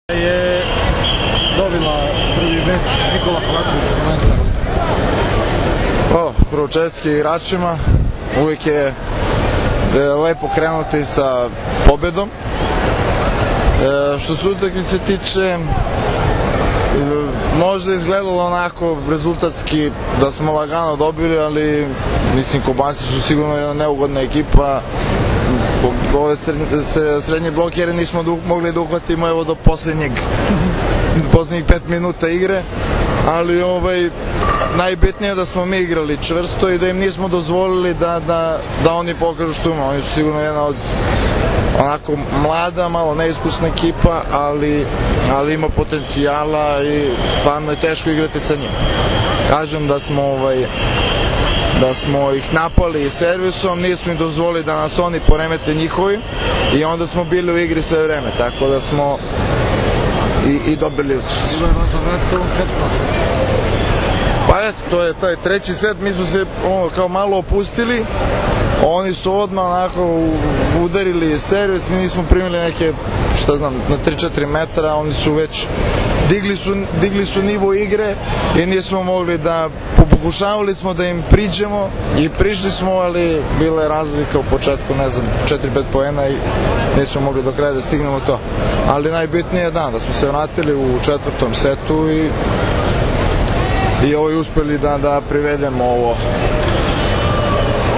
IZJAVA NIKOLE KOVAČEVIĆA